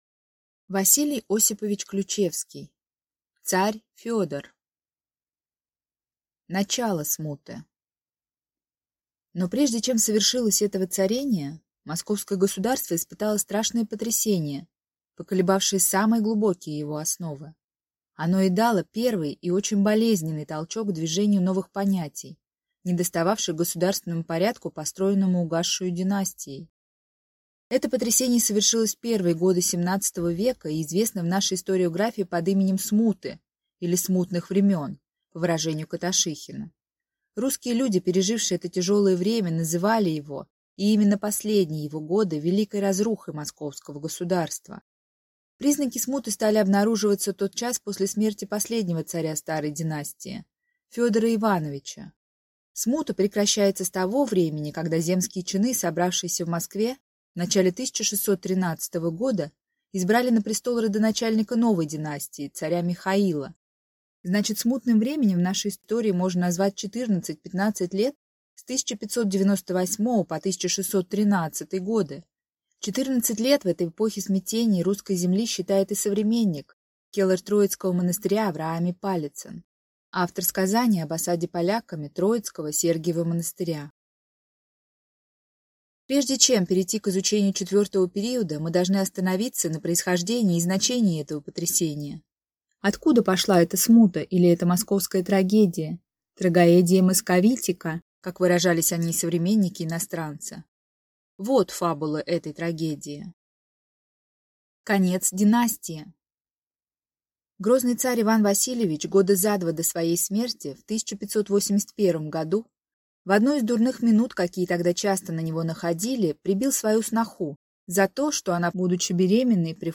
Аудиокнига Царь Федор | Библиотека аудиокниг
Прослушать и бесплатно скачать фрагмент аудиокниги